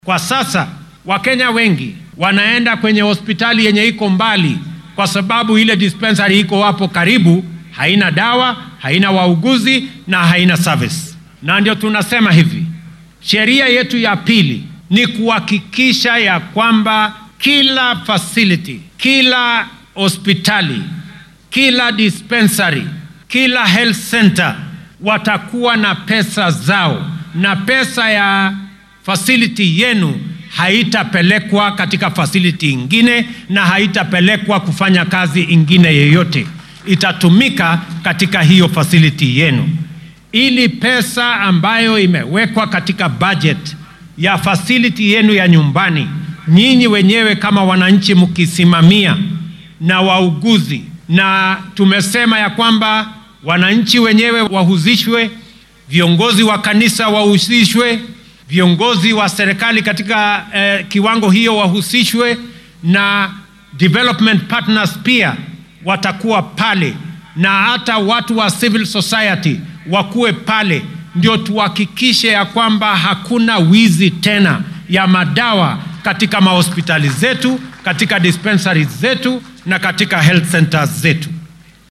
Madaxweynaha dalka William Ruto oo maanta khudbad ka jeedinayay munaasabadda xuska maalinta halyeeyada wadanka ee Mashujaa Day ayaa sheegay in dhammaan kenyaanka ay heli doonaan adeegyo caafimaad oo tayo leh, ay awoodaan sidoo kalena aan lagu kala takoorin.